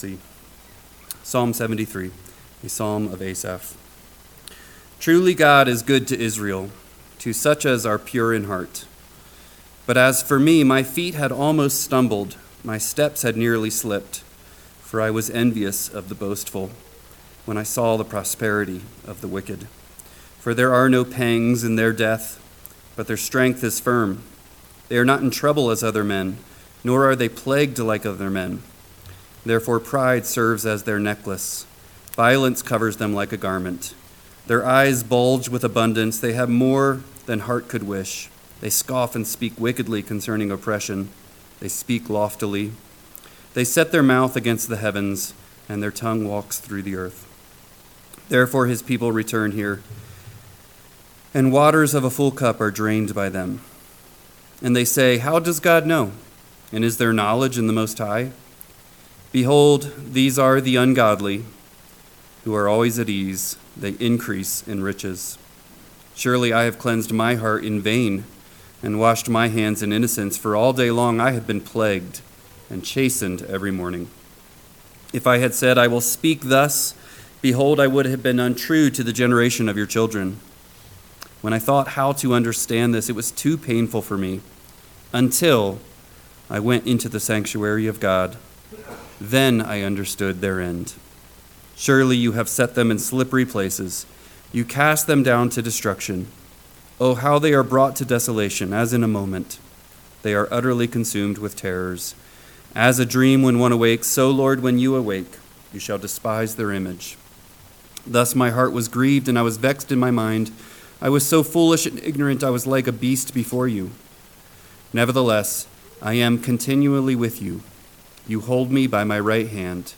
PM Sermon – 9/15/2024 – Psalm 73 – Northwoods Sermons